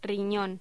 Sonidos: Voz humana